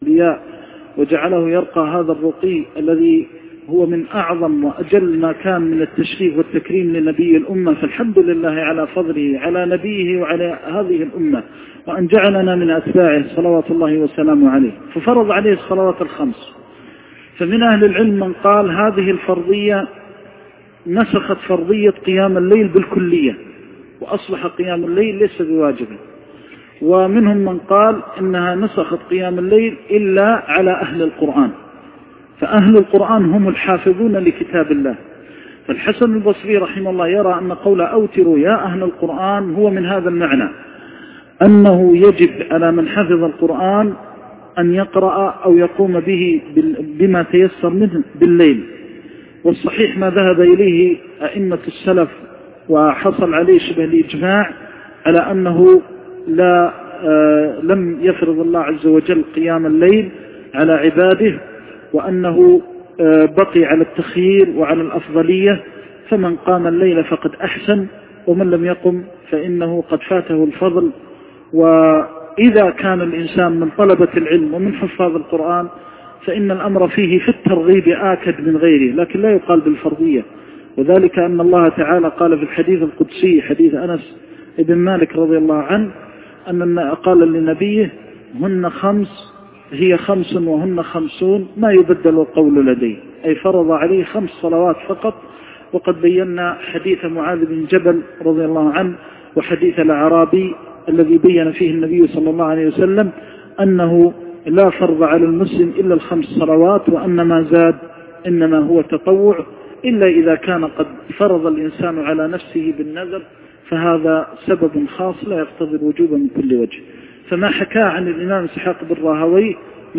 شرح سنن الترمذي - درس 227 - الشيخ محمد محمد المختار الشنقيطي